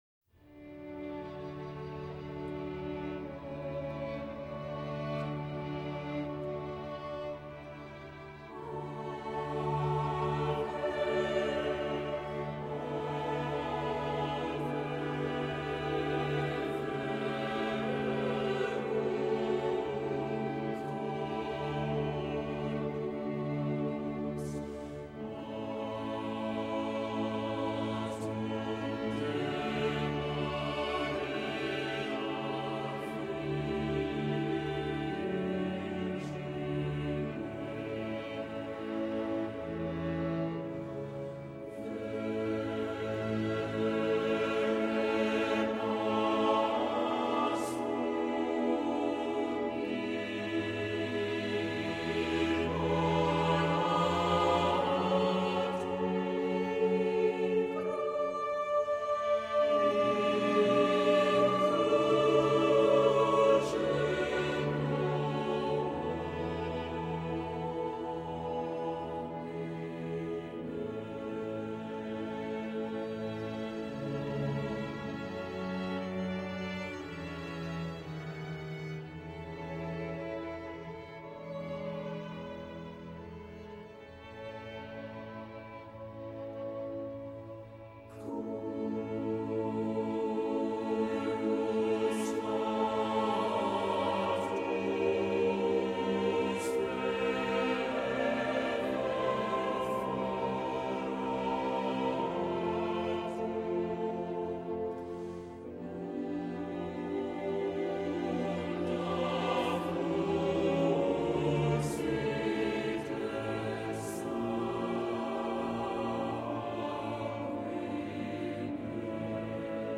Accompaniment:      Organ
Music Category:      Early Music